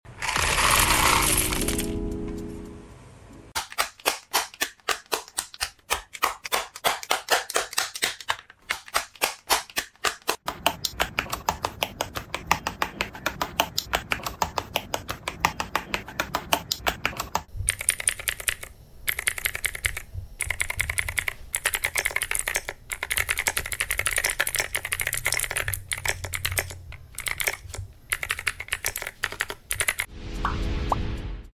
Sound Test Switch Akko Creamy Sound Effects Free Download